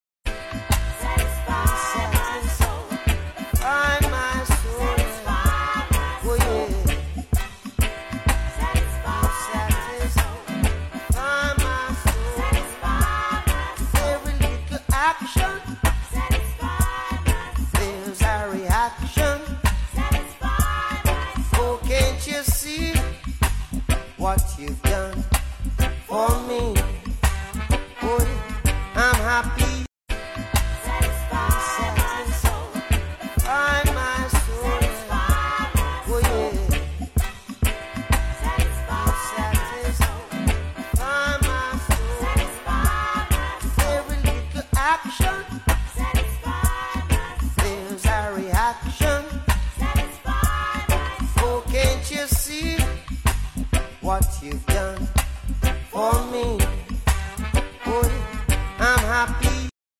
soulful reggae track
With mellow rhythms, heartfelt lyrics